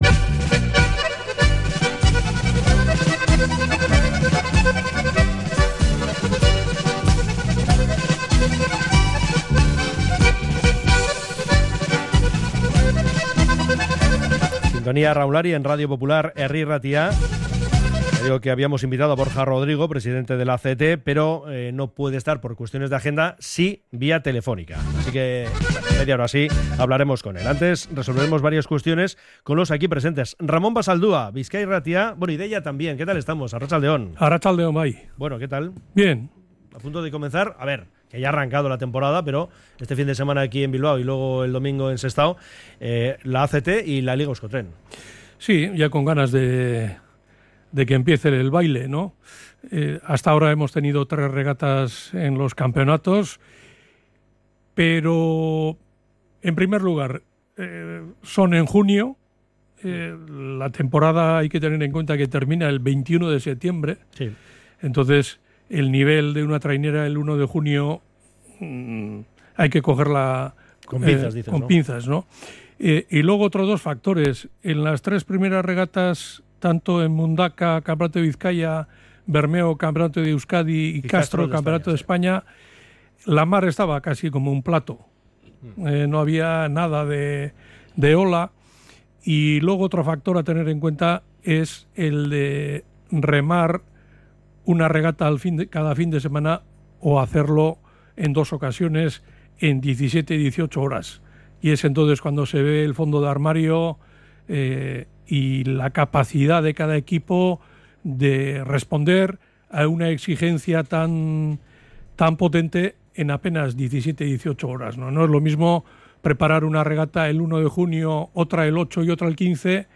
En la primera tertulia de la temporada